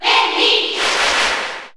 Wendy_Cheer_Japanese_SSB4_SSBU.ogg